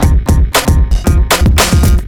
Happy 1 115-C.wav